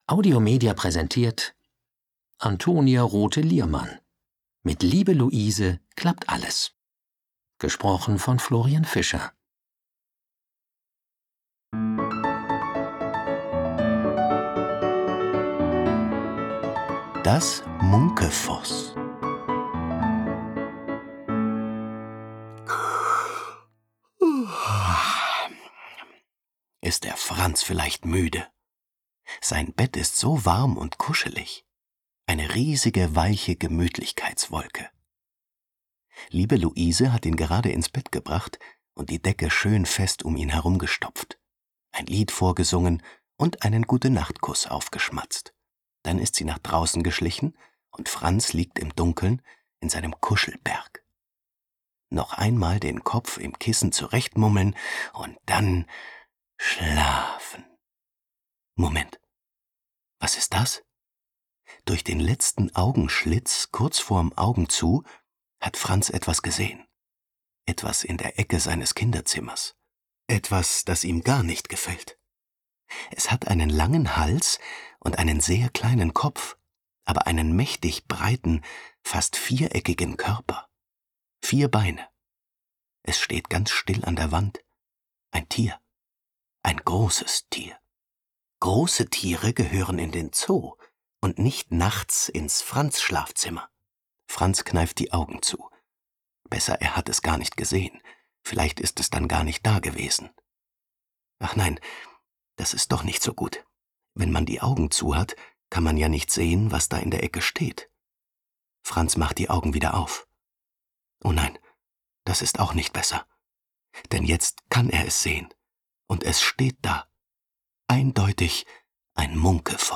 Schlagworte 4 Jahre • Angst • Deutsch • Franz • Freundin • Geschichten • Gute-Nacht-Geschichten • Hörbuch; Lesung für Kinder/Jugendliche • Junge • Mut • Spaß • Trost • Wut